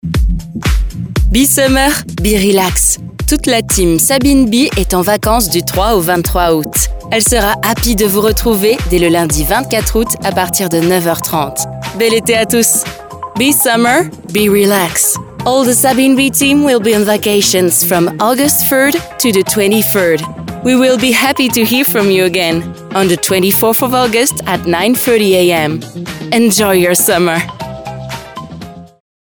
Natural, Versátil, Amable
Telefonía